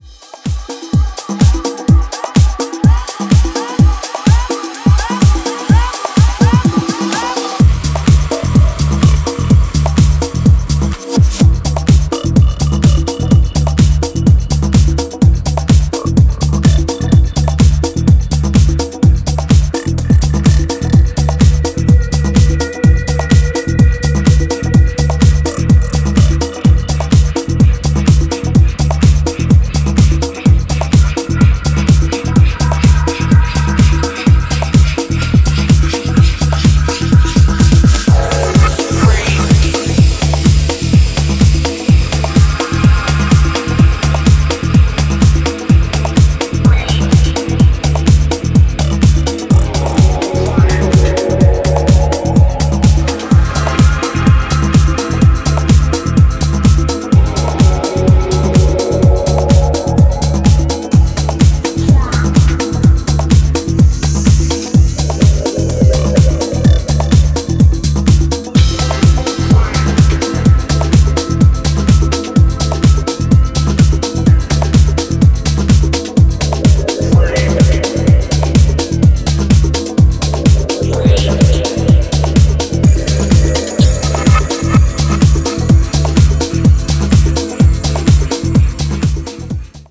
コンガロールも軽快なビートにヘヴィなベースがゴリゴリ攻めつつ、クールなパッドがたなびく
UKGからの影響も感じられるベースラインの圧の強さが新質感のモダン・ディープ・ハウスを展開